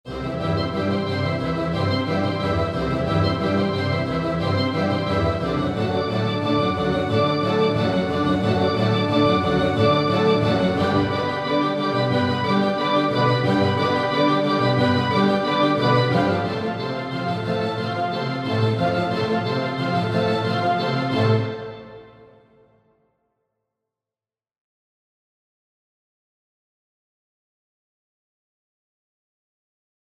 Hier die Staccato-Passage mit Dynamiksteuerung. Altiverb liefert die Kulisse des Opernhauses von Sydney: